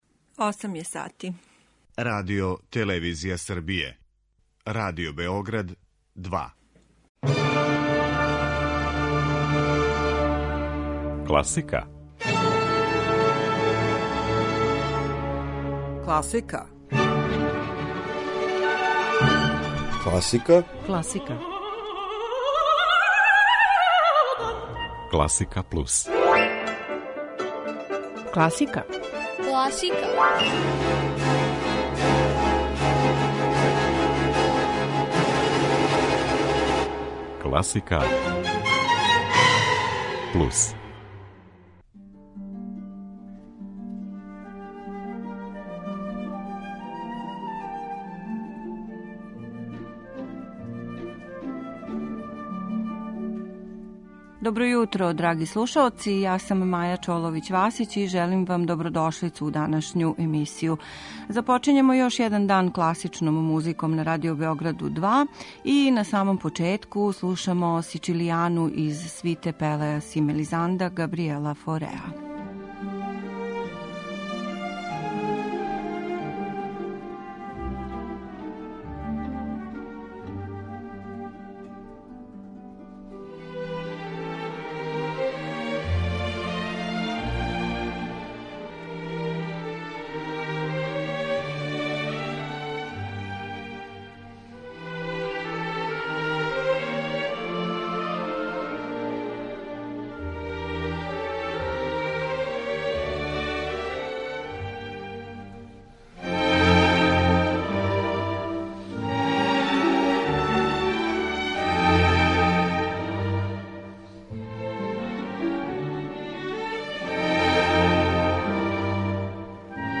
Класична музика